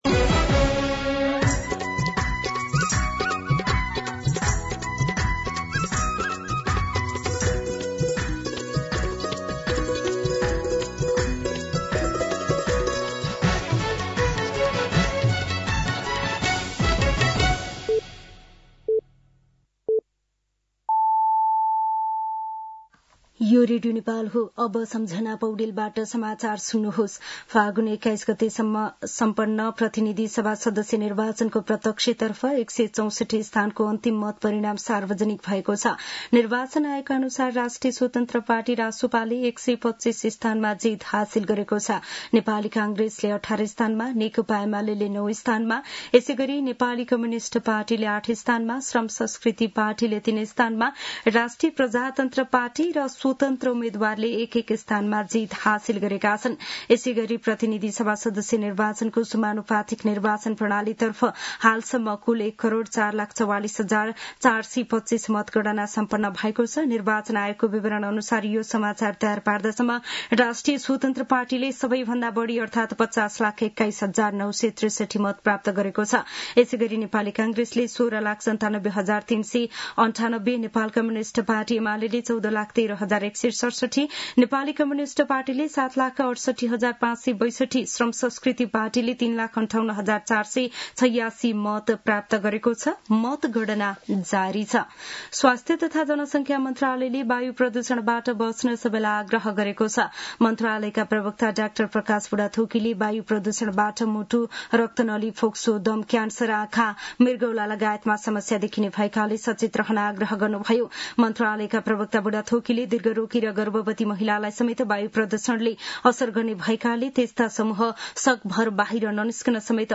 दिउँसो १ बजेको नेपाली समाचार : २६ फागुन , २०८२
1pm-News-11-26.mp3